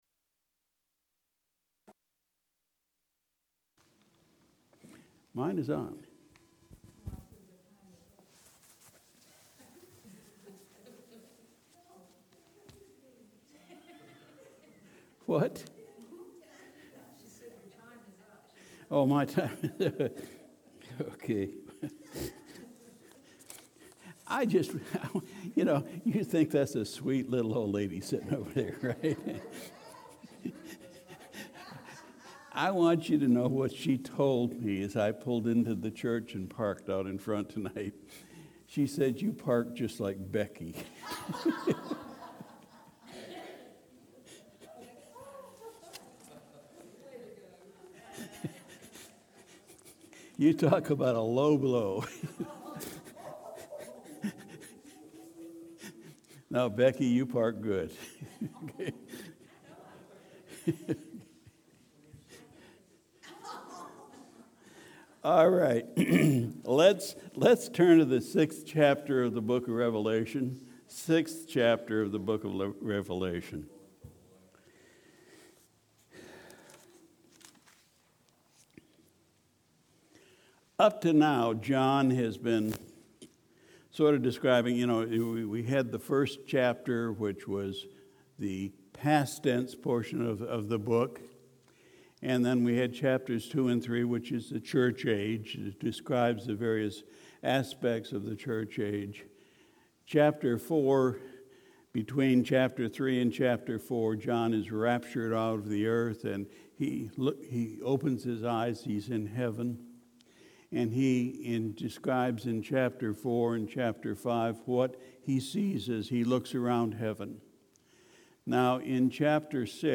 December 1, 2019 Sunday Evening Service We continued our study in Revelation